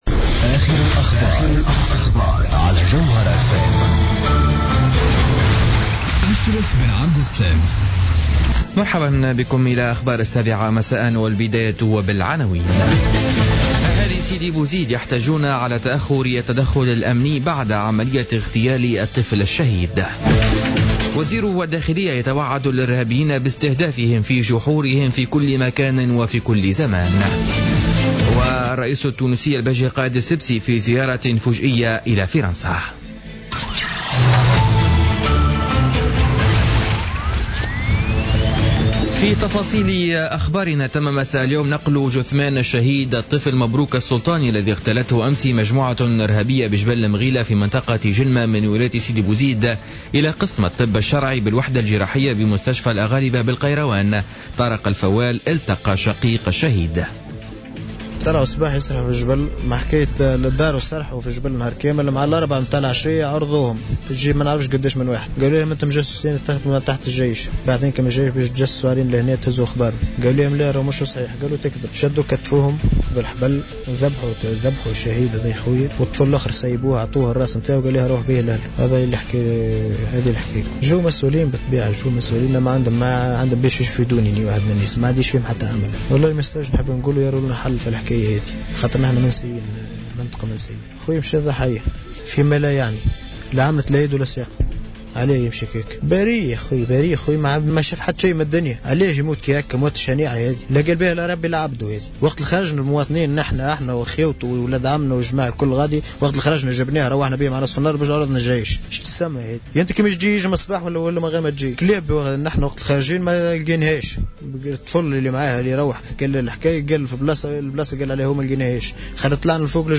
نشرة أخبار الساعة السابعة مساء ليوم السبت 14 نوفمبر 2015